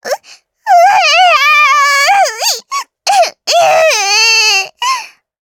Taily-Vox_Sad_jp.wav